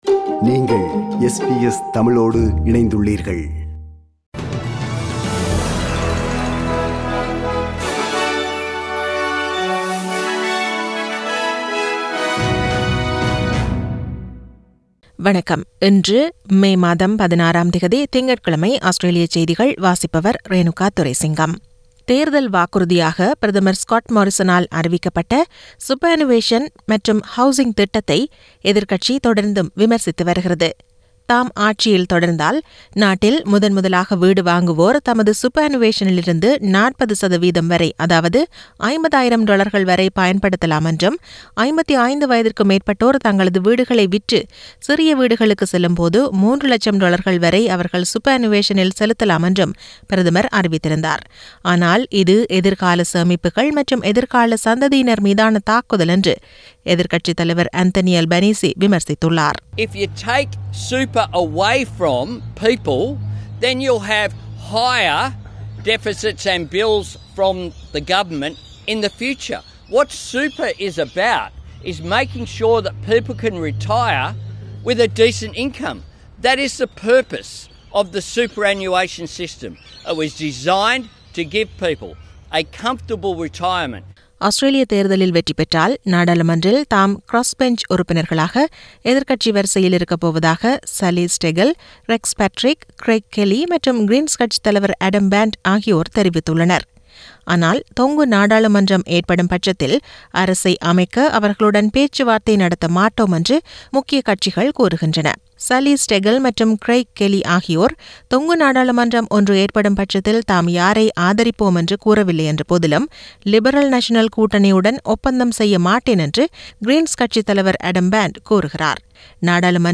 Australian news bulletin for Monday 16 May 2022.